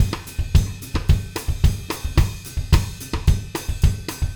Звуки латино
Latin Drumbeat 11 Groove